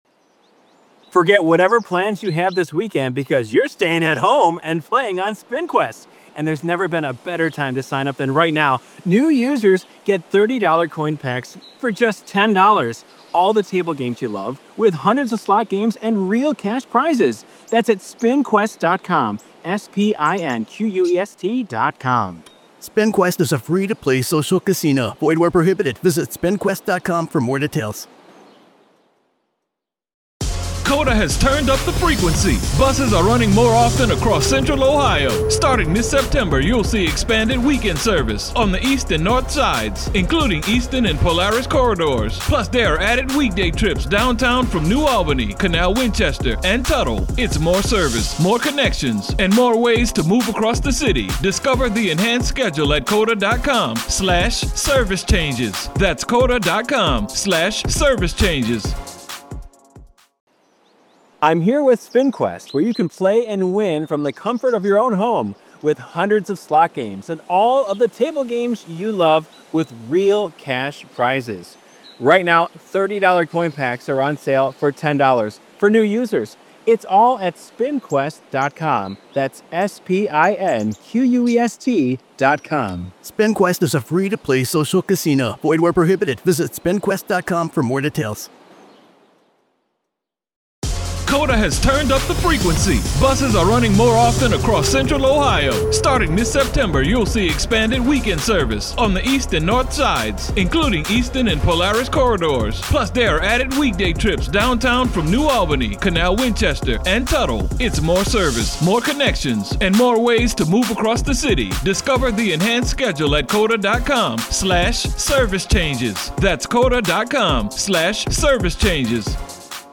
True Crime Today | Daily True Crime News & Interviews / Sadistic Plans: PI Claims Disturbing Discovery in Home Linked to D4vd